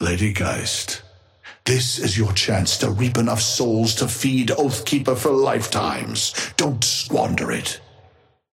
Amber Hand voice line - Lady Geist, this is your chance to reap enough souls to feed Oathkeeper for lifetimes.
Patron_male_ally_ghost_oathkeeper_5a_start_01.mp3